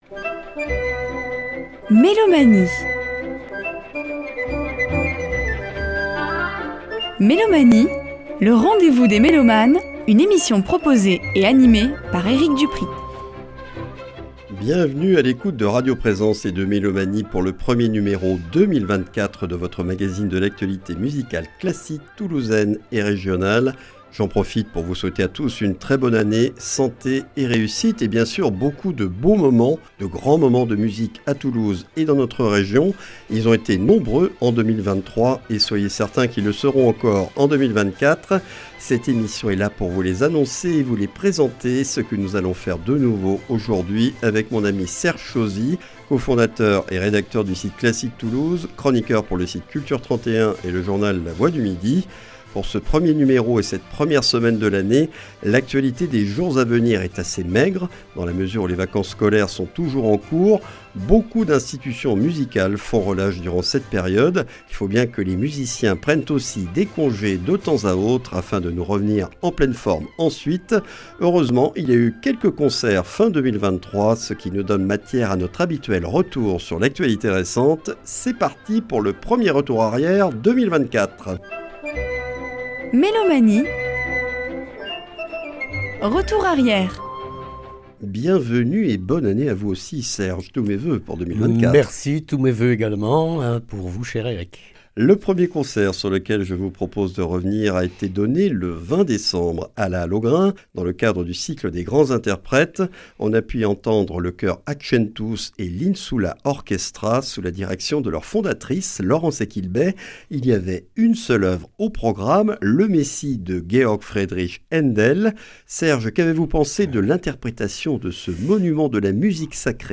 Mélomanie(s) et ses chroniqueurs présentent l'actualité musicale classique de notre région.